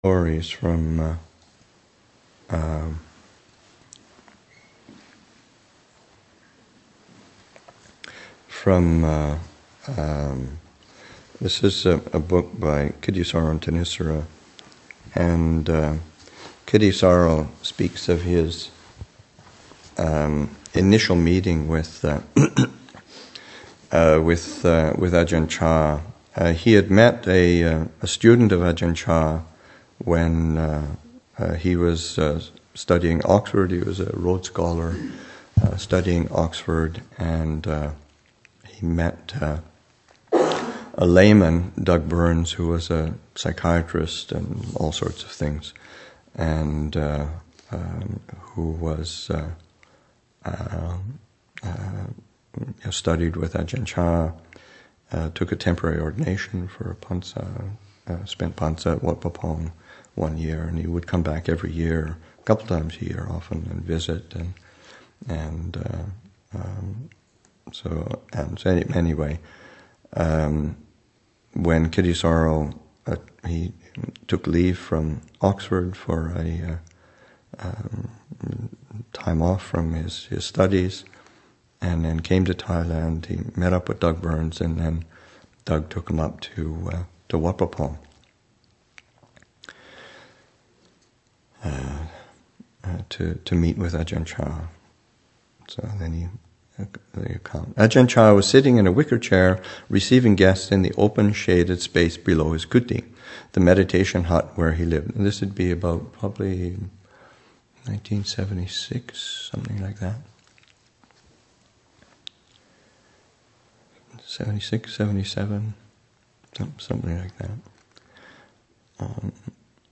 Readings from the Introduction to Listening to the Heart